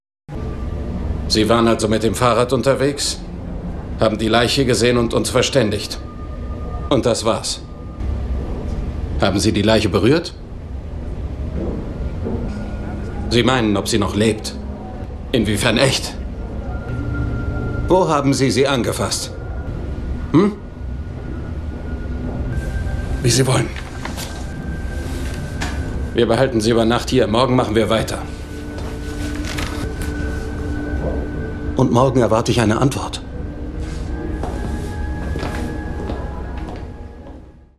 Mittel plus (35-65)
Lip-Sync (Synchron)